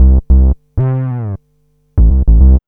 2408L B-LOOP.wav